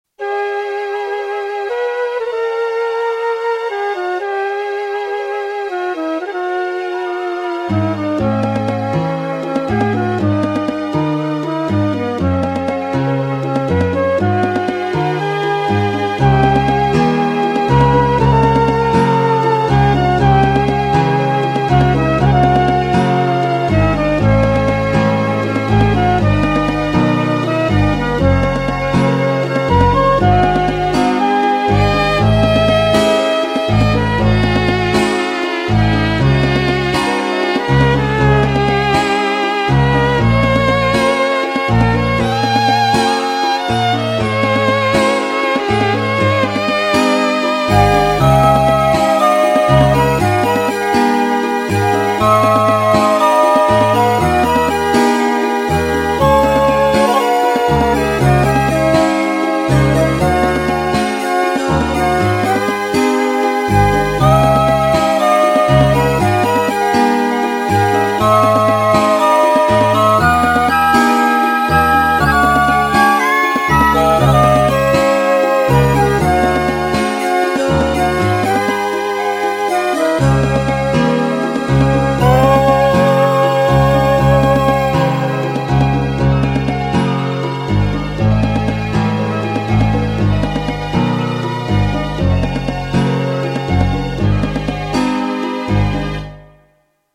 ナオコでいろんなMIDIを鳴らしてみた。
一部の楽器はすごくいい音が鳴るが、
それ以外は妙にチープだったり、そもそも鳴らなかったりする。